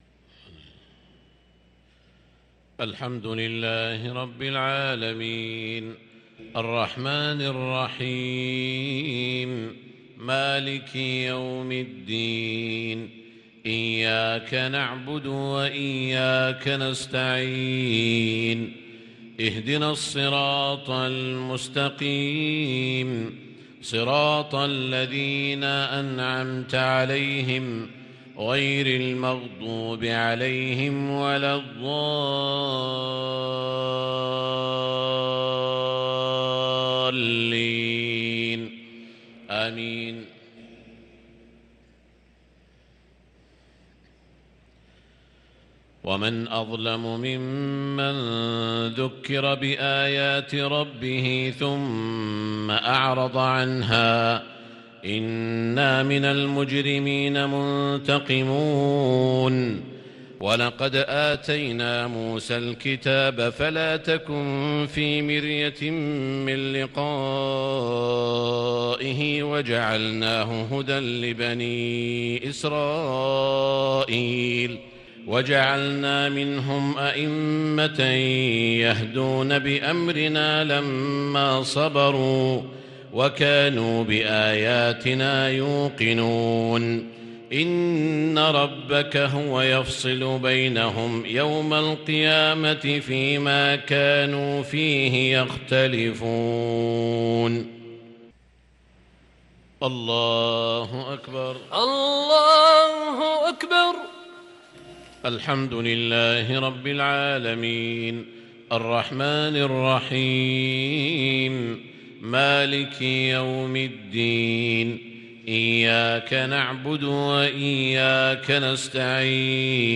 صلاة المغرب للقارئ سعود الشريم 8 ربيع الأول 1444 هـ
تِلَاوَات الْحَرَمَيْن .